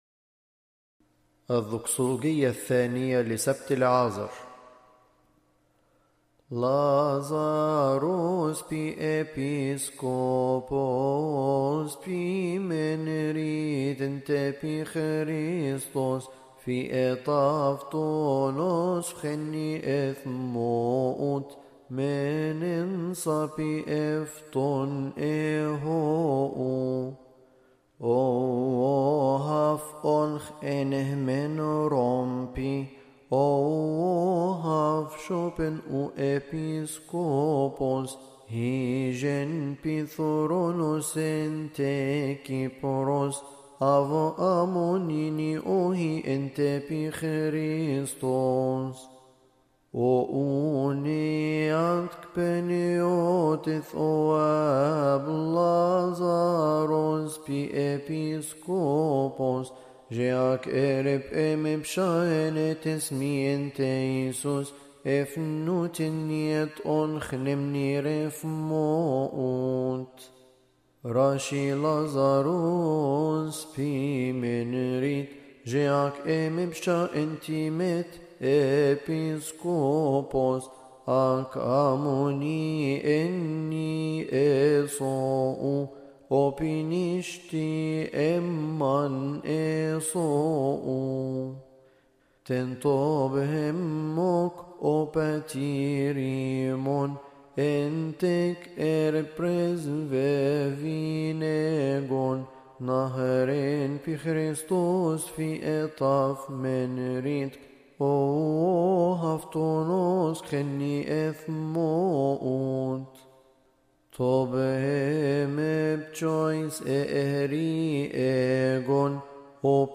لحن: الذكصولوجية الثانية لسبت لعازر